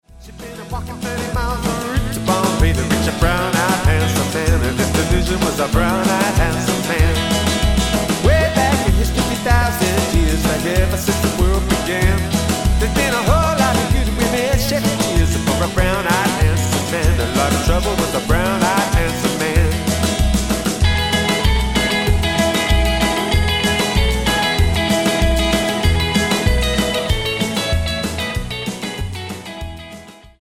Tonart:E Multifile (kein Sofortdownload.
Die besten Playbacks Instrumentals und Karaoke Versionen .